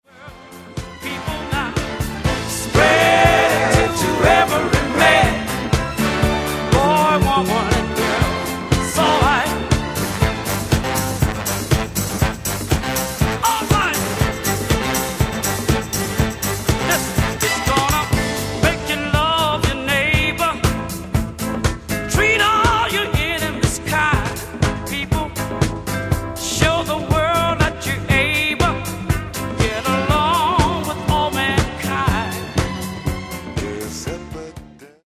Genere:   Philly Sound | Soul